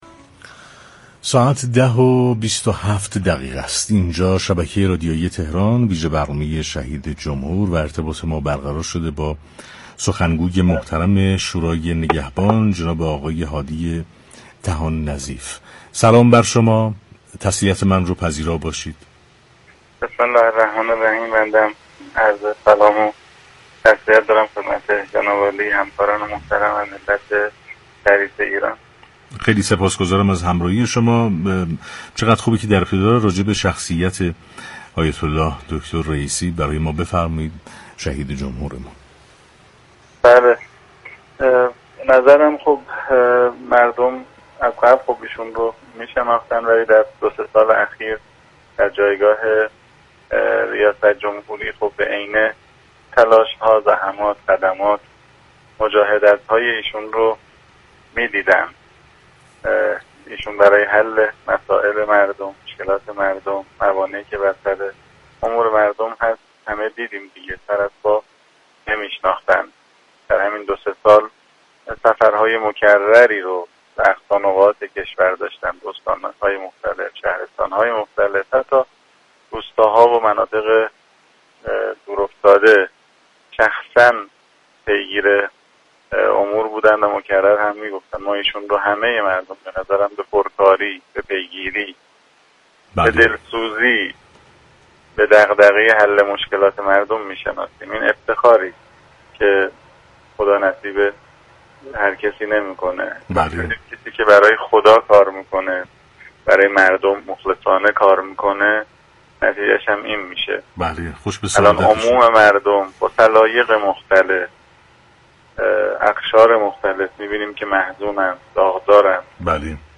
به گزارش پایگاه اطلاع رسانی رادیو تهران، هادی طحان نظیف عضو حقوقدان و سخنگوی شورای نگهبان در گفت و گو با ویژه برنامه «شهید جمهور» اظهار داشت: مردم كشورمان رئیس جمهور را به پركاری، دلسوزی و پیگیر حل دغدغه‌ها و مشكلات خودشان می‌شناسند.